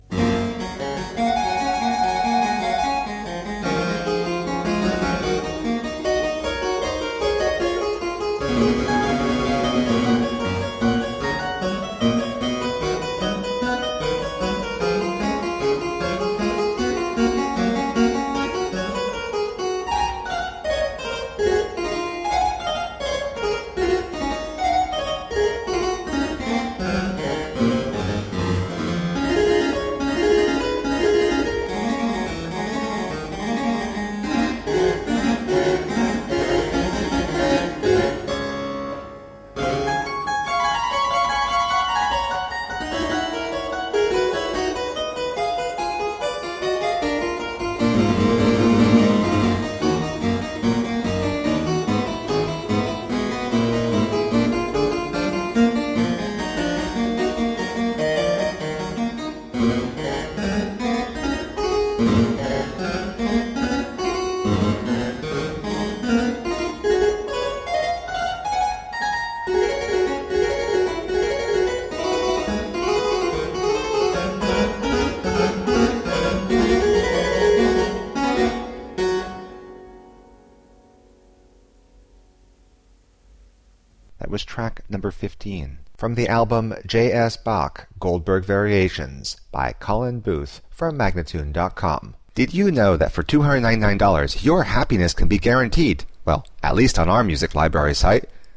Solo harpsichord music.
Classical, Baroque, Instrumental, Harpsichord